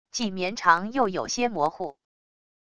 既绵长又有些模糊wav音频